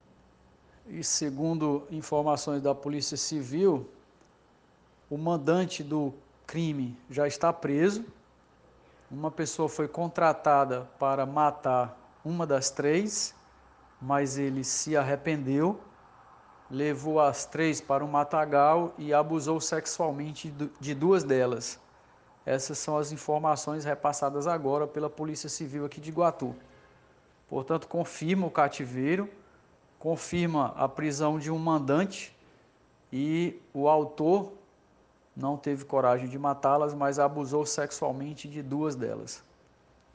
Áudio-do-tenente-coronel-confirmando-as-informações.mp3